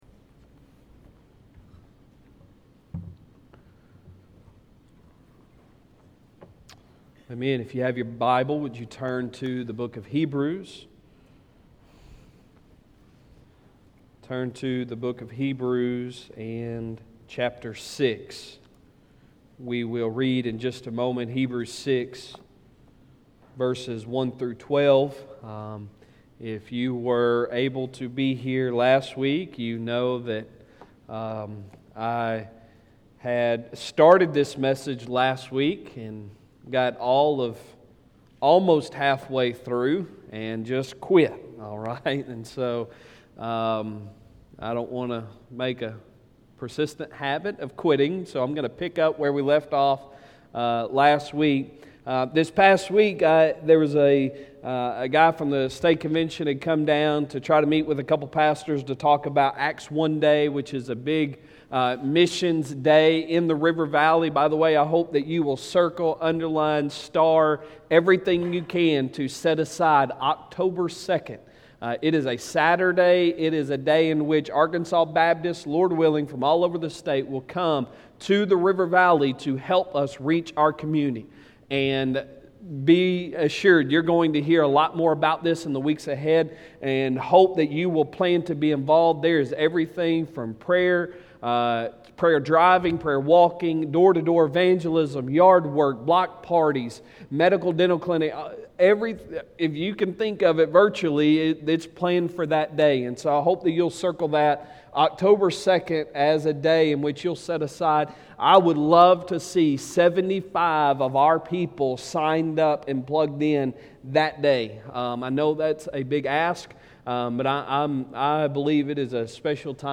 Sunday Sermon July 11, 2021